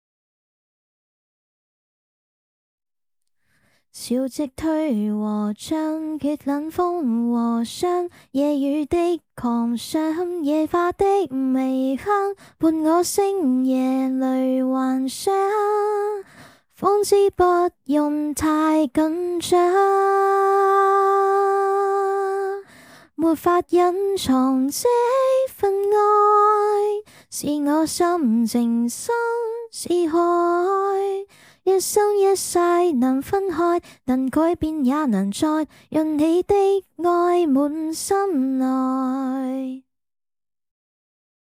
对粤语支持很好的一款少女音RVC模型
数据集是使用了多个粤语音色融合而成，在使用的时候跳转音调可能会出现御姐音等。
唱歌表现